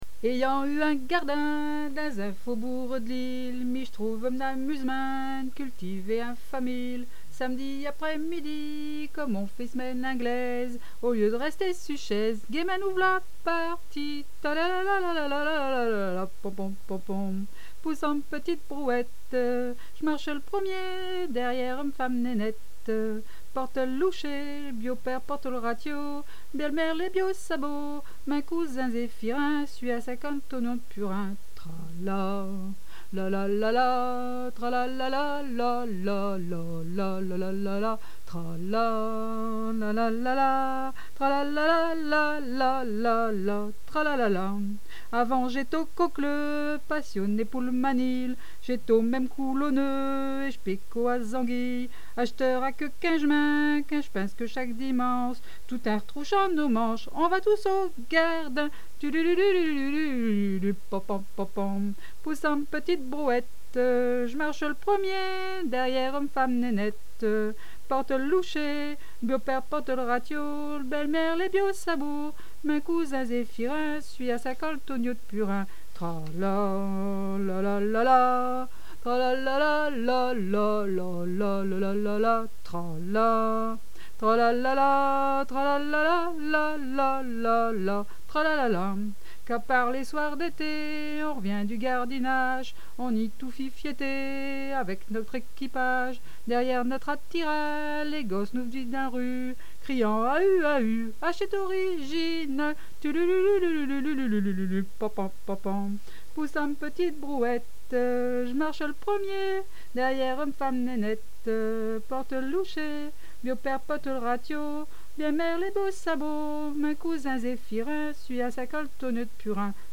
chanson du nord "poussant m'petite brouette..." les paroles
et ...vous pouvez m'écouter la chanter